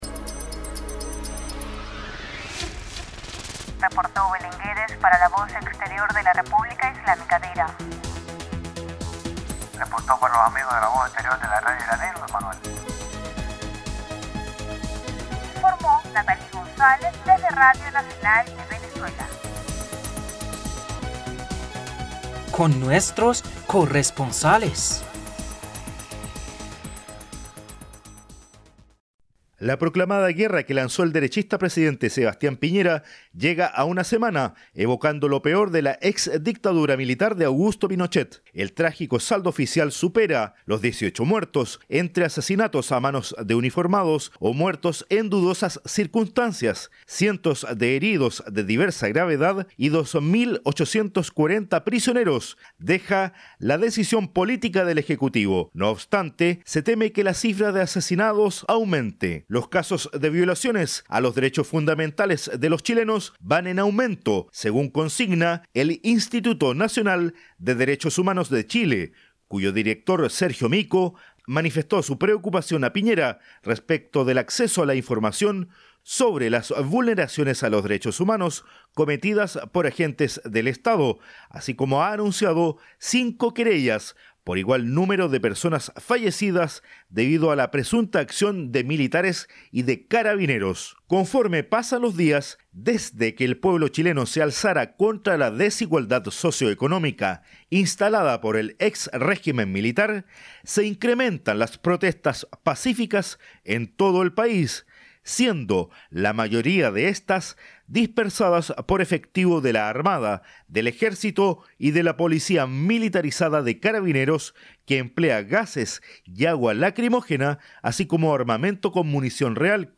REPORTE: Continúa indignación del pueblo chileno contra la desigualdad social